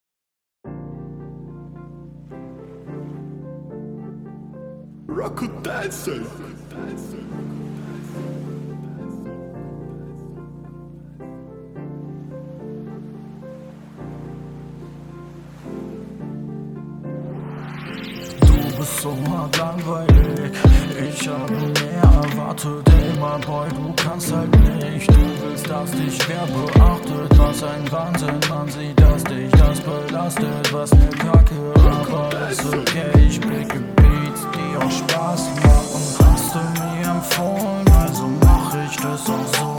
selbes problem wie runde 1. das klingt nicht geil mit dem effekt. da hat mir …